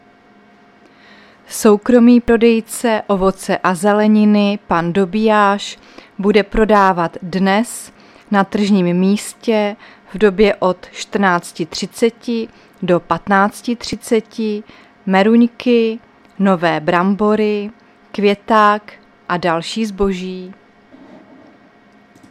Záznam hlášení místního rozhlasu 19.7.2024
Zařazení: Rozhlas